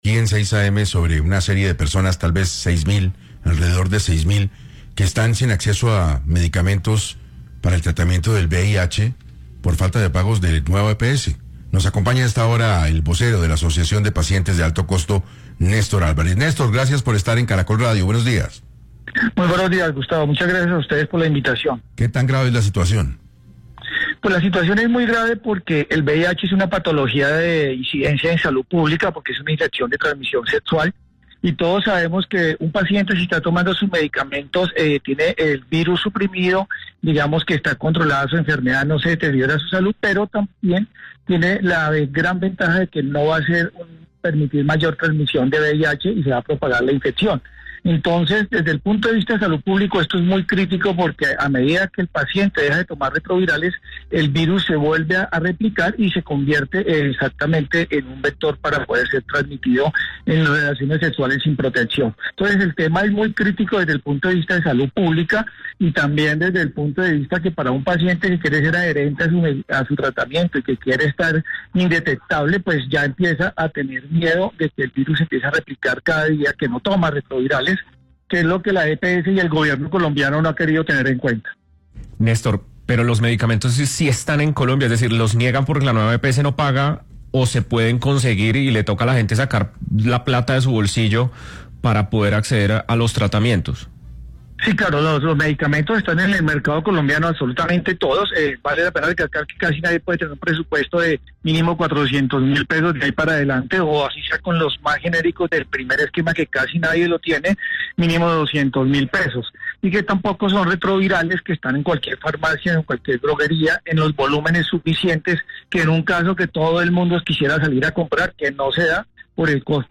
Una crítica denuncia sobre el desabastecimiento de medicamentos antirretrovirales para miles de pacientes con VIH en Colombia fue expuesta en los micrófonos de Caracol Radio, en 6AM.
Durante la entrevista